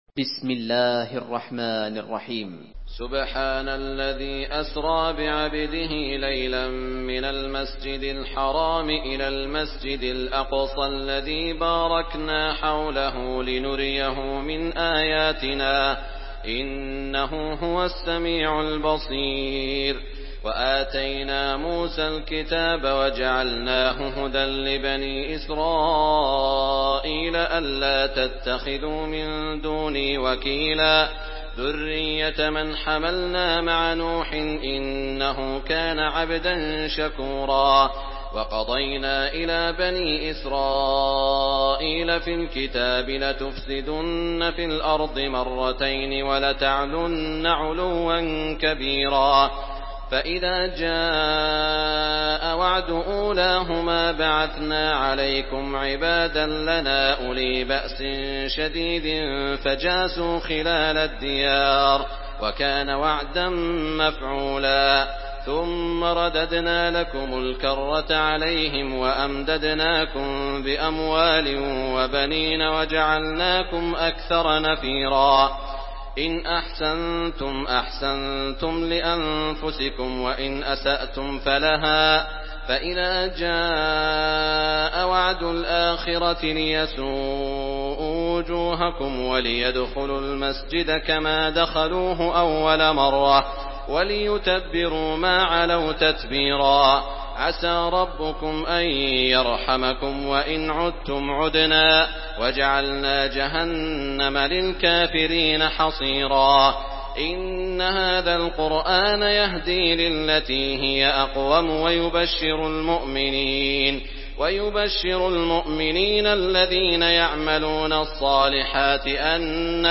Surah Al-Isra MP3 by Saud Al Shuraim in Hafs An Asim narration.
Murattal Hafs An Asim